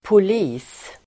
Uttal: [pol'i:s]